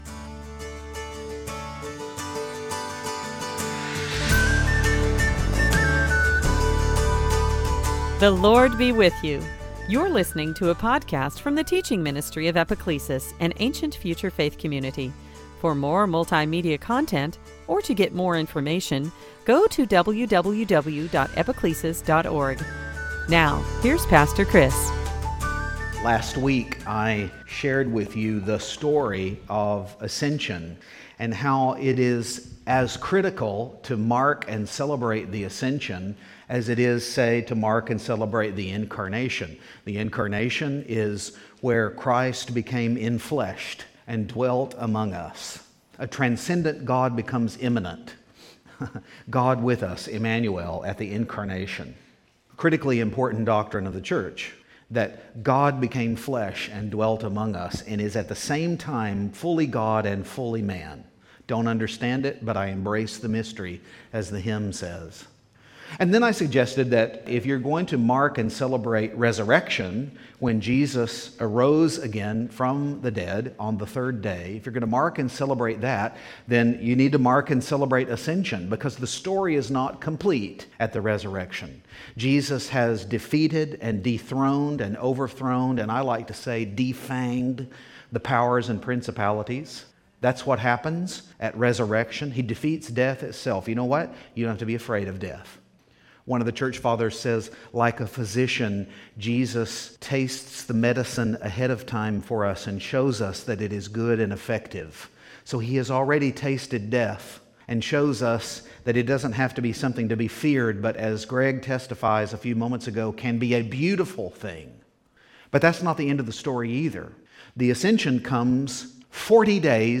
Pentecost Sunday